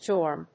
/dˈɔɚm(米国英語), dˈɔːm(英国英語)/